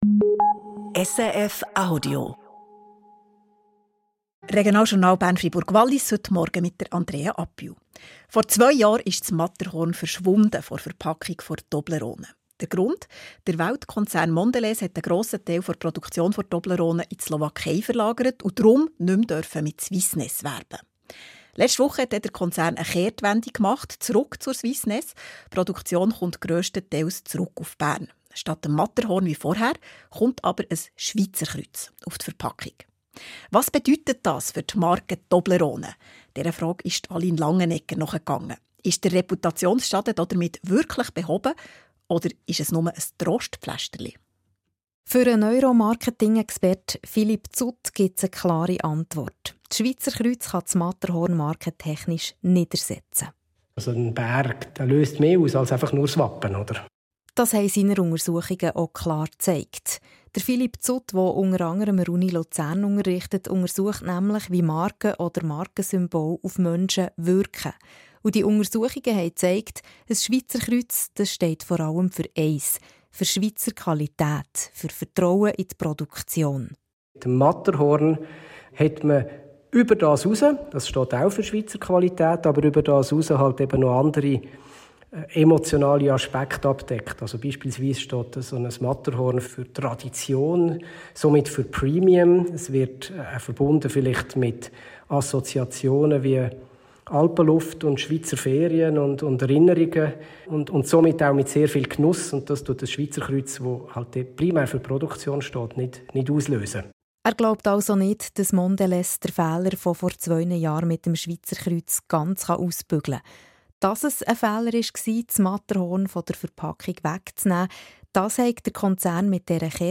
SRF-Interview zu Toblerone: Reicht das Schweizerkreuz als Swissness-Ersatz?